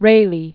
(rālē), Third Baron.